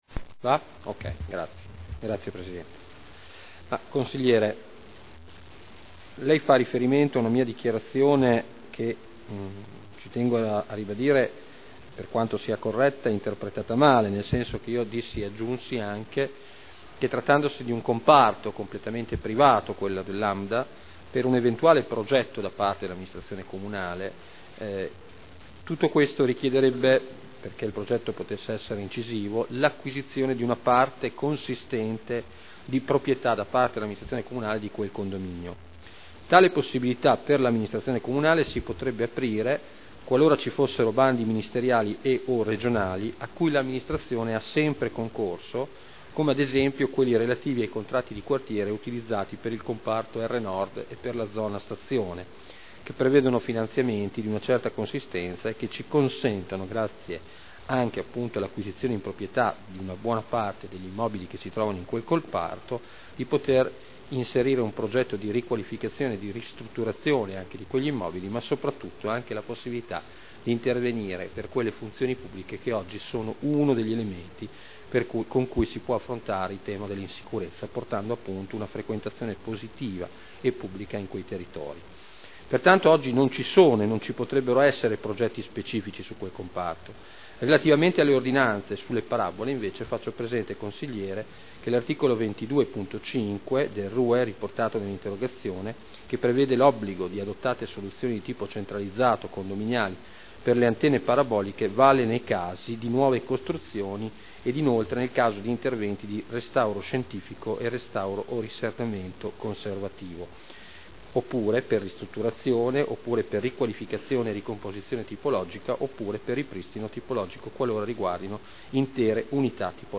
Seduta del 10/01/2011. Risponde a interrogazione del consigliere Barcaiuolo (PdL) avente per oggetto: “Riqualificazione Lambda”